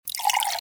pourWater.mp3